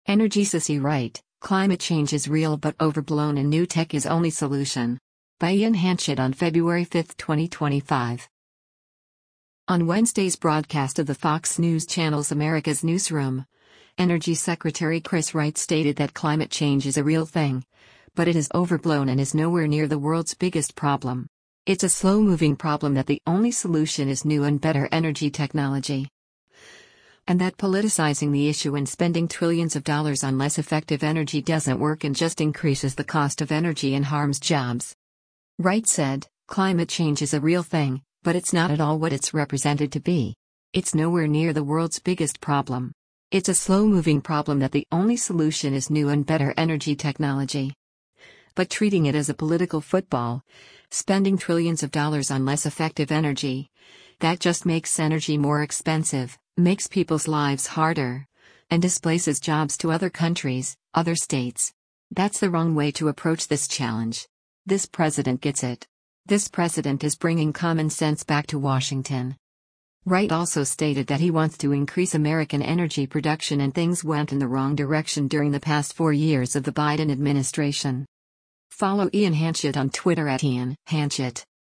On Wednesday’s broadcast of the Fox News Channel’s “America’s Newsroom,” Energy Secretary Chris Wright stated that “Climate change is a real thing,” but it is overblown and is “nowhere near the world’s biggest problem. It’s a slow-moving problem that the only solution is new and better energy technology.” And that politicizing the issue and “spending trillions of dollars on less effective energy” doesn’t work and just increases the cost of energy and harms jobs.